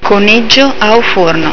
(pronuncia)   coniglio
Cuniggiu_au_furnu.au